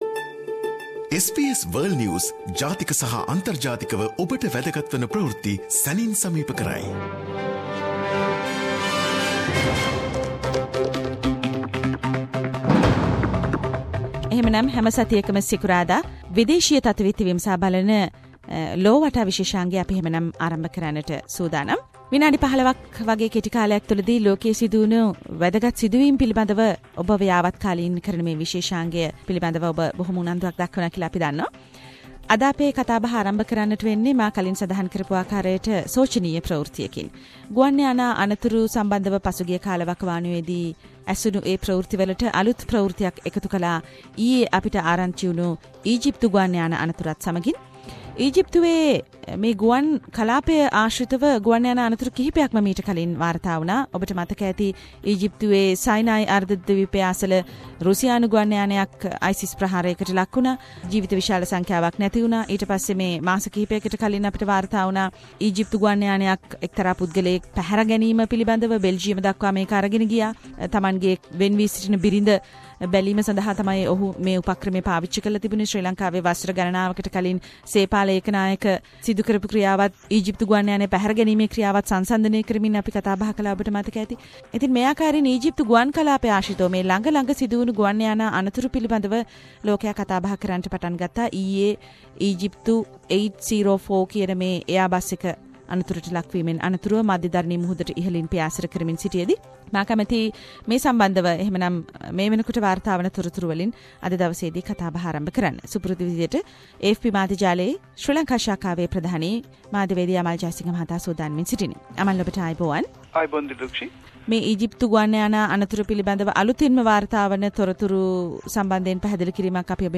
SBS Sinhalese weekly world news wrap